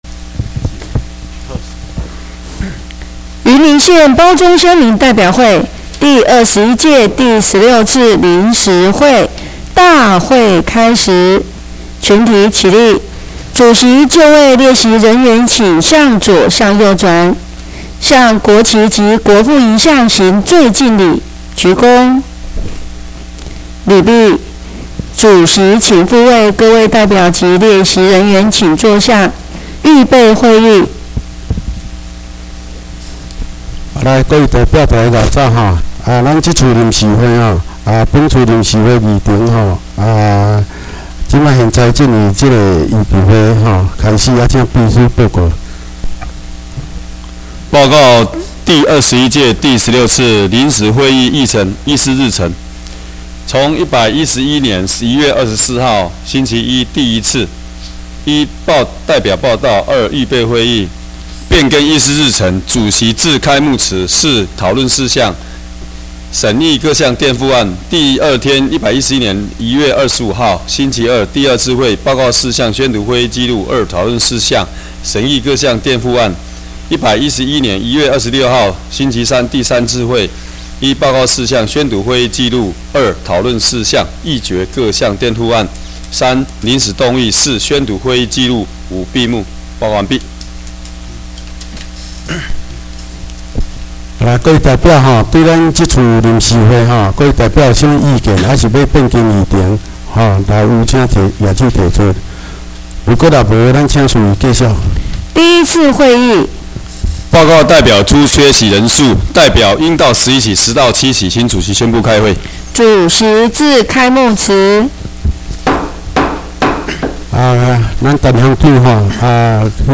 第21屆第16次臨時會第1天.MP3